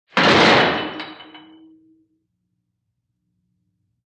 PrisonCellDoorSlam PE802001
DOORS VARIOUS PRISON DOORS: Cell door slam, medium fast.